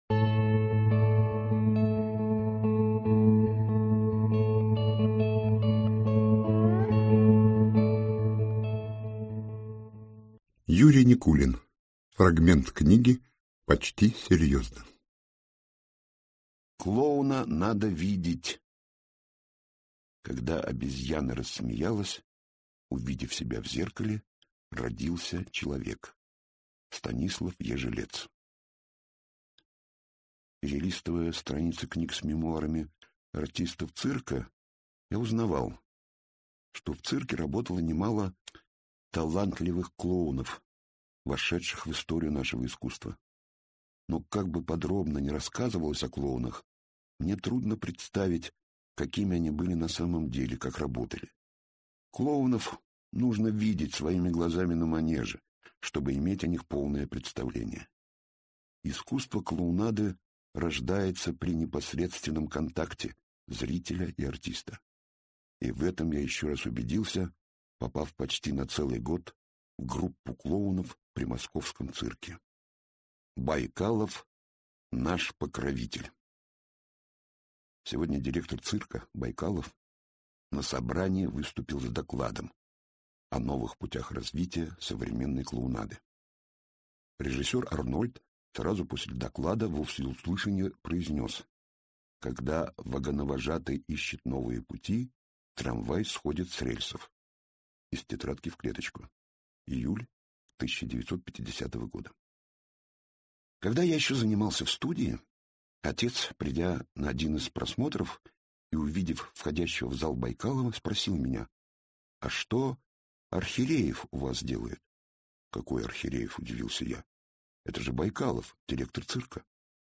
Аудиокнига Наш второй дом | Библиотека аудиокниг
Прослушать и бесплатно скачать фрагмент аудиокниги